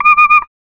Garbage Beeper 3.wav